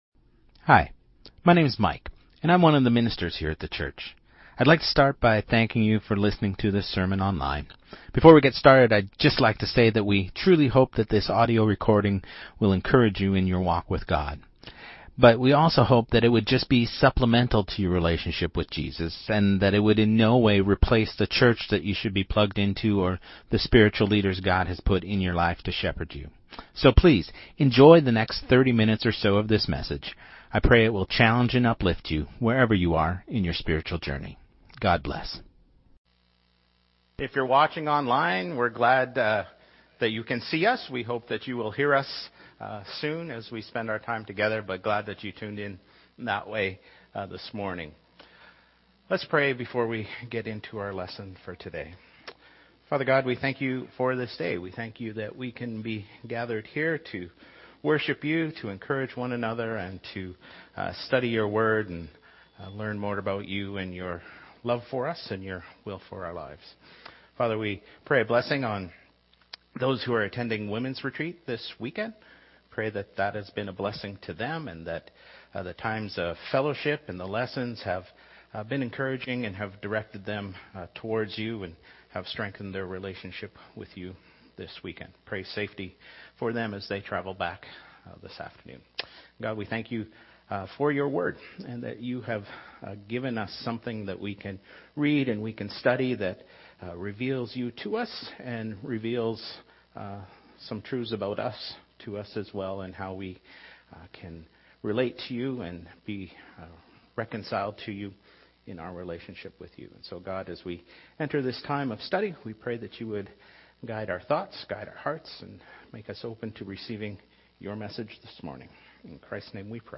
Sermons | Church of Christ Saskatoon